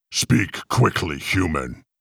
13. Speak Quickly.wav